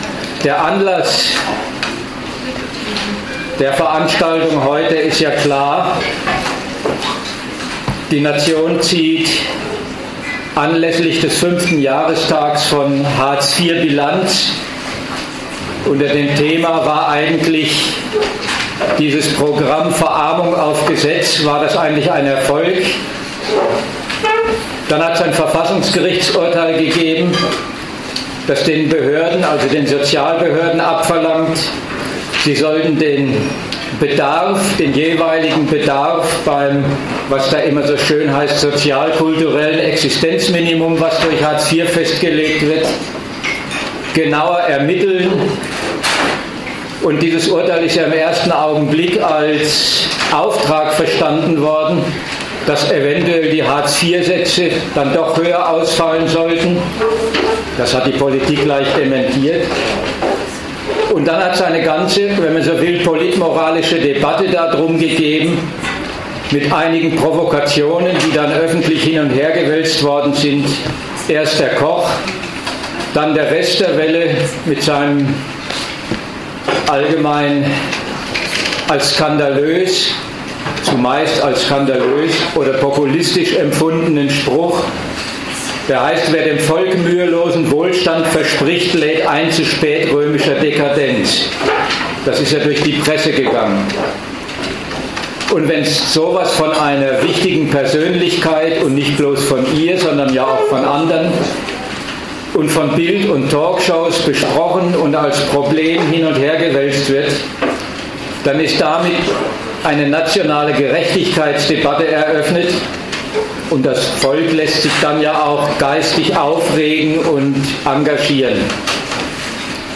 München
Gastreferenten der Zeitschrift GegenStandpunkt